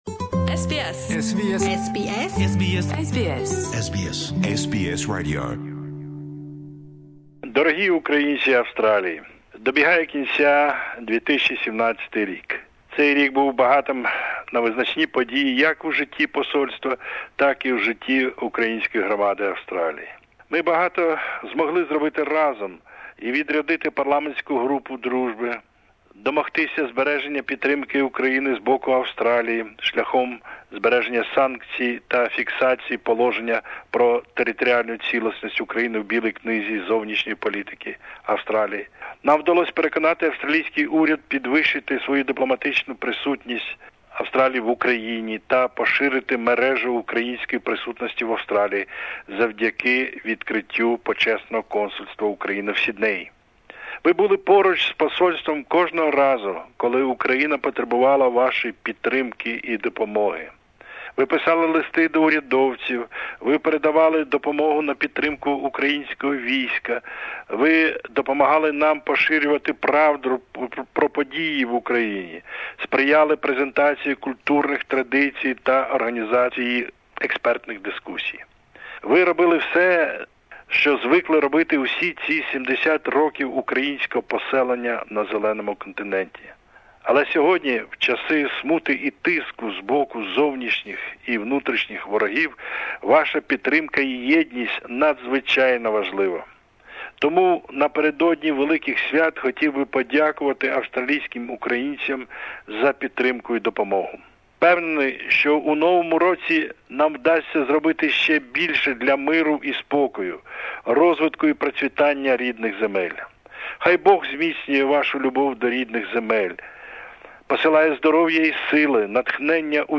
New Year and Christmas Greetings from Ukrainian Ambassador Dr M. Kulinich